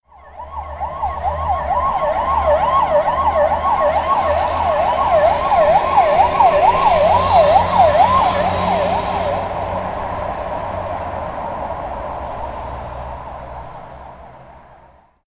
دانلود صدای ماشین پلیس 2 از ساعد نیوز با لینک مستقیم و کیفیت بالا
جلوه های صوتی